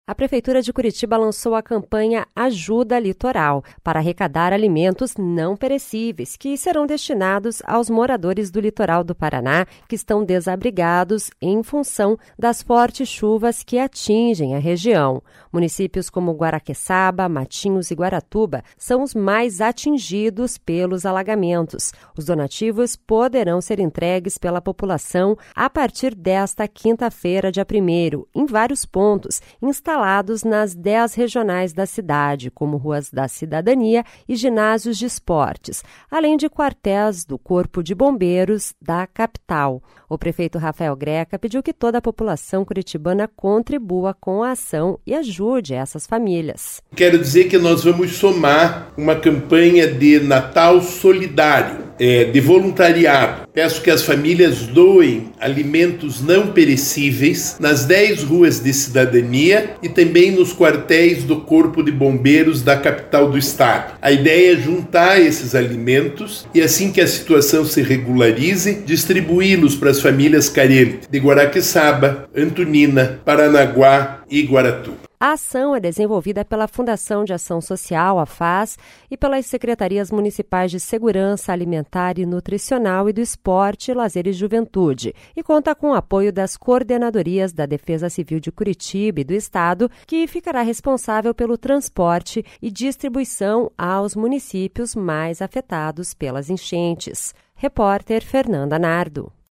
O prefeito Rafael Greca pediu que toda a população curitibana contribua com ação e ajuda as famílias.